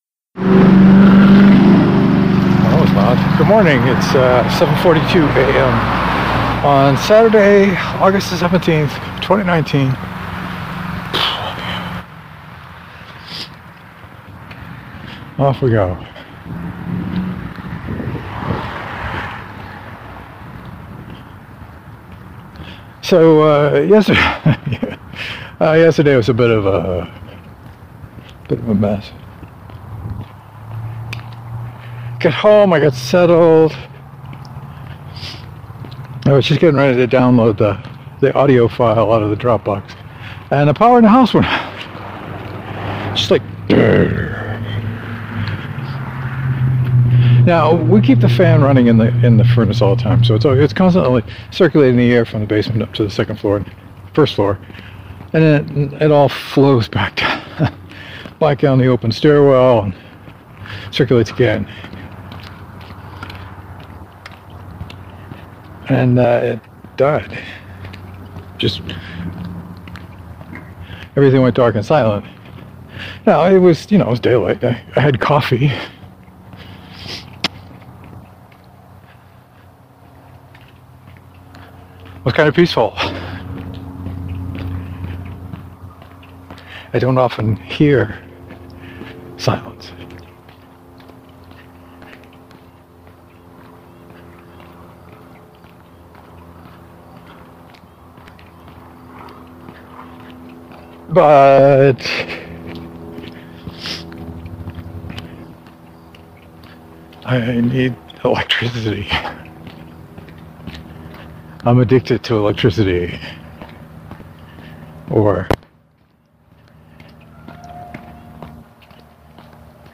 The weather was lovely this morning and – being Saturday – traffic was down. Made for a pleasant walk.